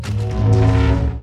Download Avengers Thanos Infinity Guntlet 2 sound effect for free.